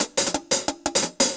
Index of /musicradar/ultimate-hihat-samples/175bpm
UHH_AcoustiHatB_175-03.wav